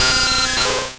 Cri de Nosferapti dans Pokémon Rouge et Bleu.